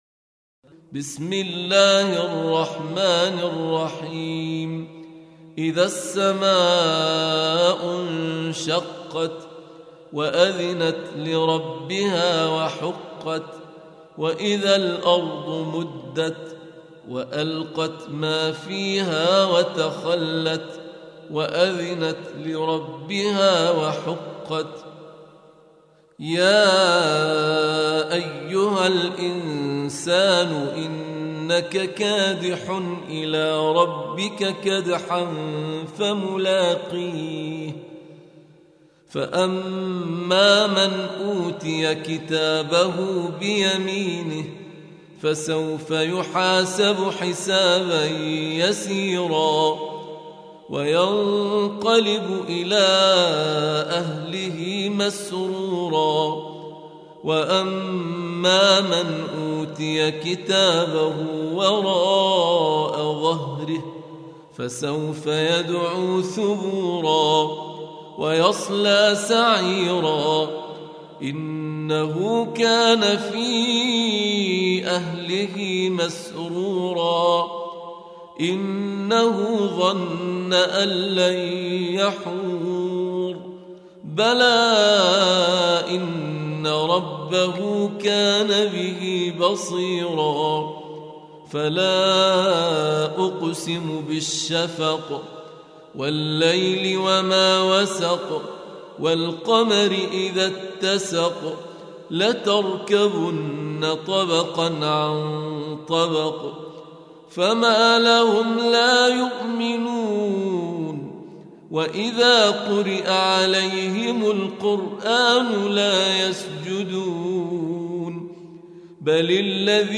Surah Sequence تتابع السورة Download Surah حمّل السورة Reciting Murattalah Audio for 84. Surah Al-Inshiq�q سورة الإنشقاق N.B *Surah Includes Al-Basmalah Reciters Sequents تتابع التلاوات Reciters Repeats تكرار التلاوات